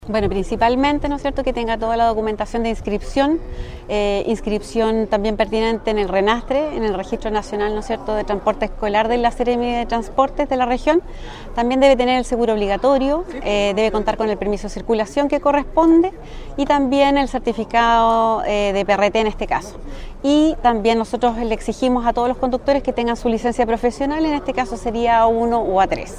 En tanto, la directora regional de Fiscalización de Transportes, María Lorena Guerrero, hizo hincapié en el llamado a quienes van a requerir de este servicio.